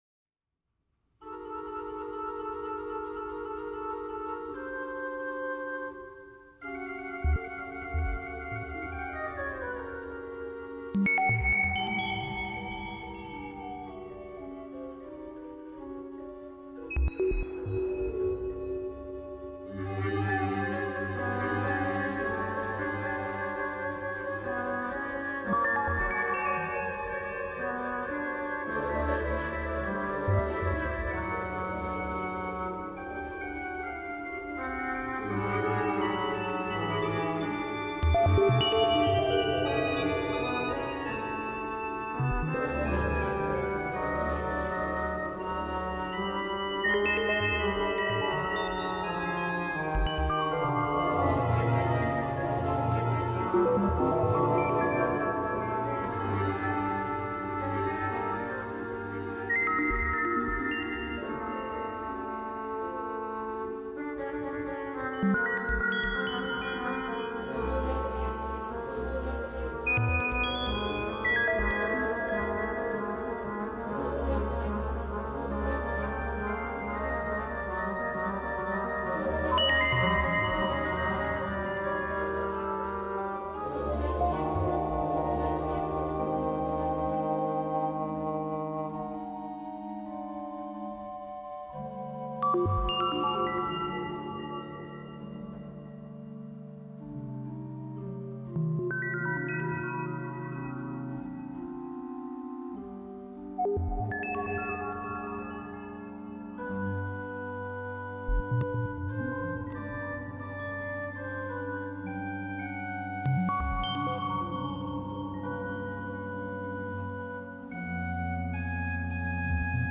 Magnuskerk Anloo sample set along with synthesizer.